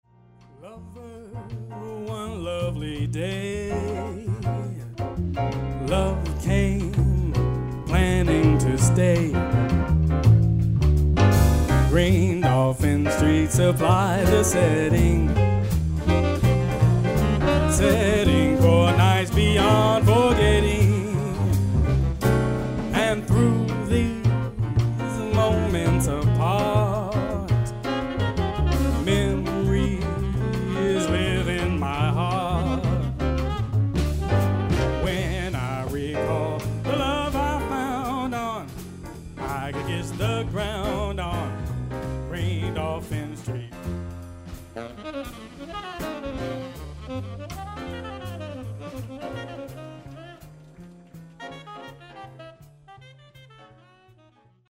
A throw back to Jazz Crooning!